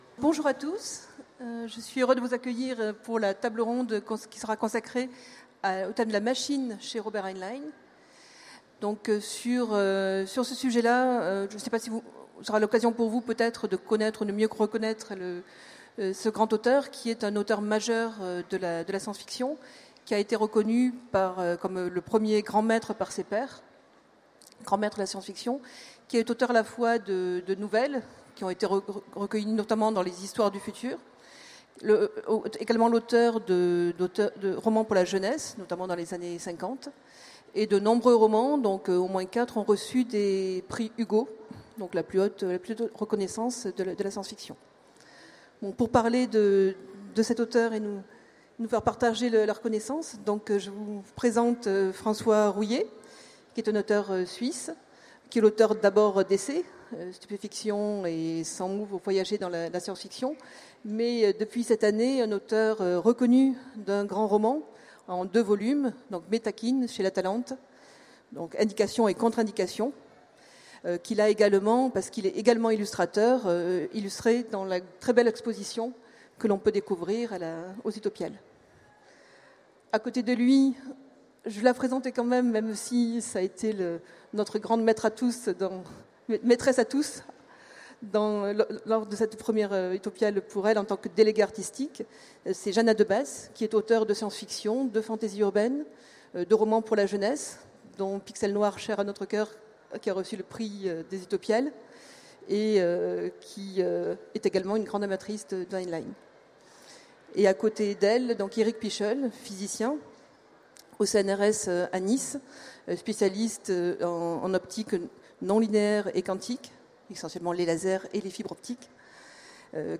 Utopiales 2016 : Conférence La machine chez Heinlein